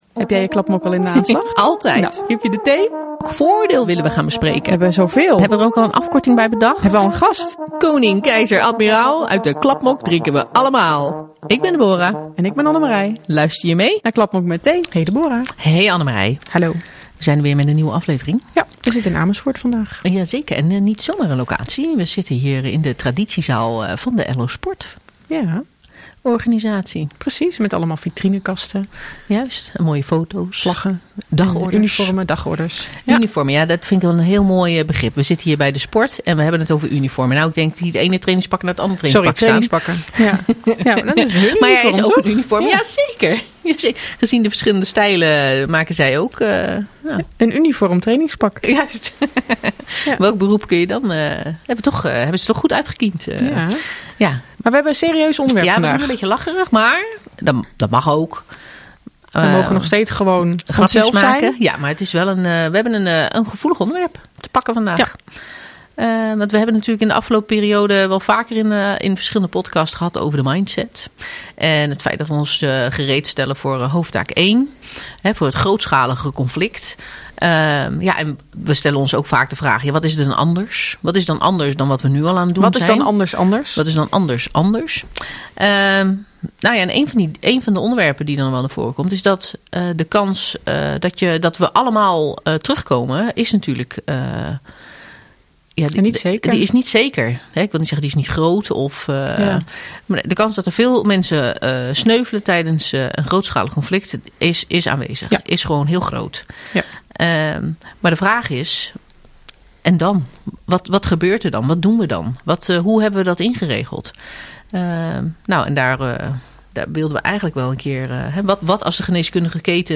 Ze discussiëren, spreken gasten en lachen vooral heel veel. … continue reading 90 afleveringen # Maatschappij # Conversaties # Overheid # Landmacht FM